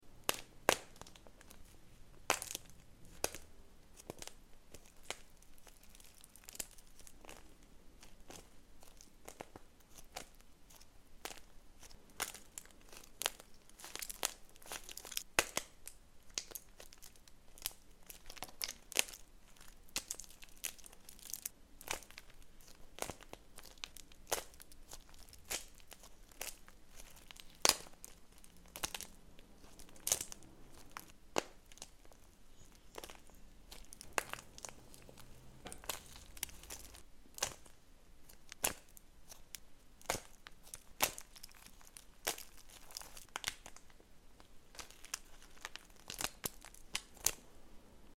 wax cracking only compilation ✨ sound effects free download